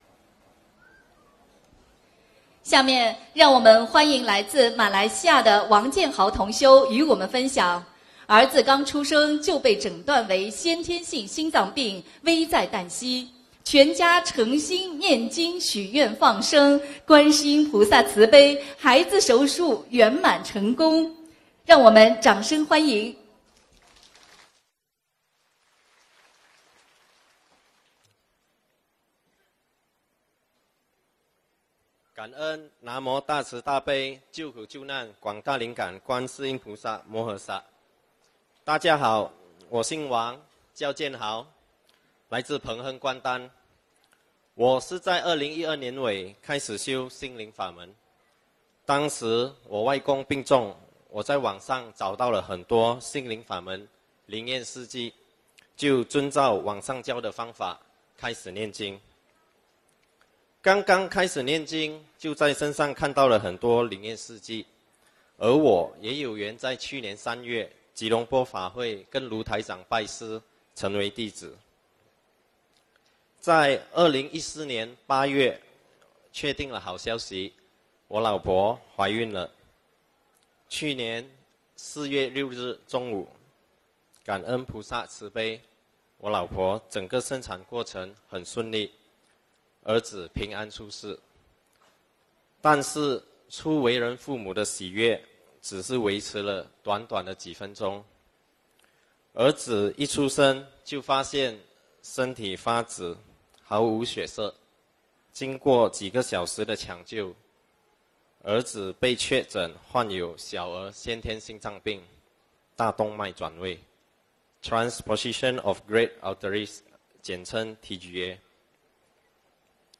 音频：刚出生的孩子先天性心脏病，全家念经让手术圆满成功--2016年2月18日印尼巴淡岛